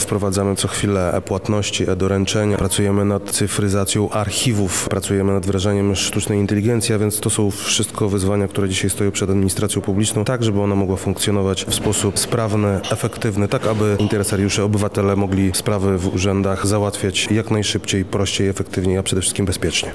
Dzisiaj (4 marca) odbyło się spotkanie pod hasłem „Cyfrowy samorząd – Lubelskie”.
krzysztof komorski – mówi Krzysztof Komorski, Wojewoda Lubelski.